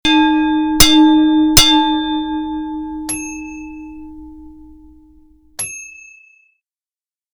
Boxing Bell Sound Effect
A boxing bell being hit 3 times before or after a boxing match. No sound other than the bell (very good clean recording).
BoxingBell3.mp3